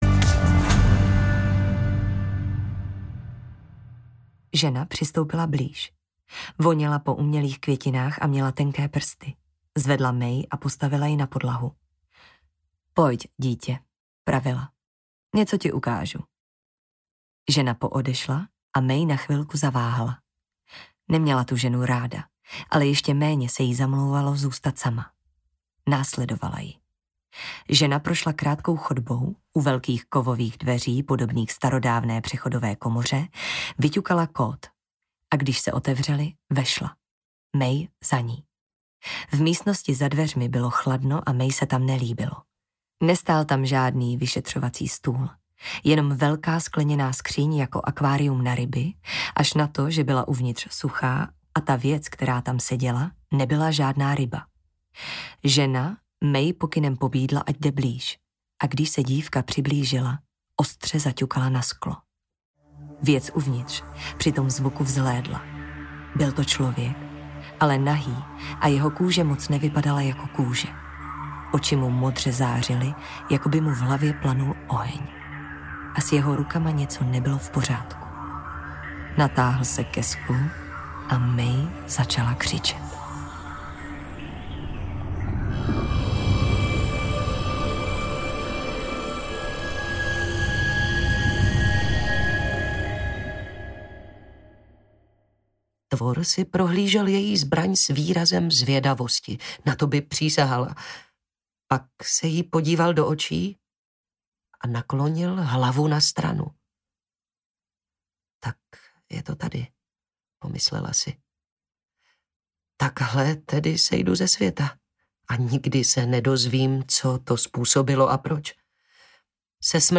Kalibánova válka audiokniha
Ukázka z knihy